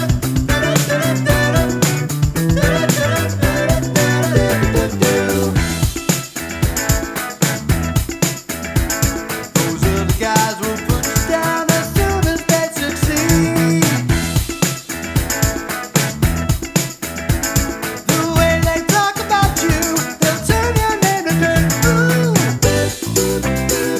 for duet Disco 3:14 Buy £1.50